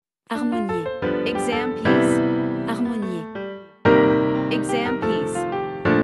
• 人声数拍